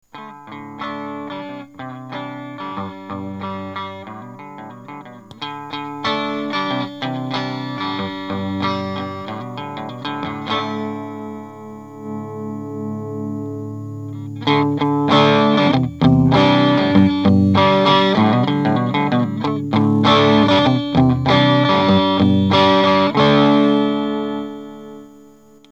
clean booster